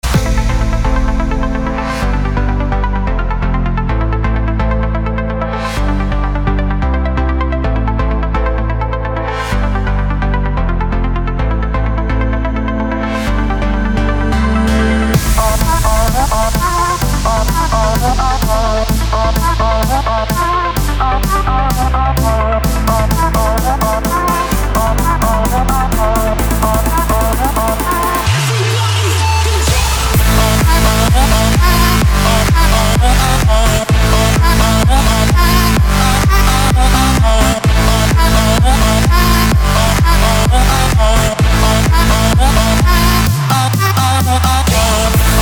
Основной стиль: Electro House.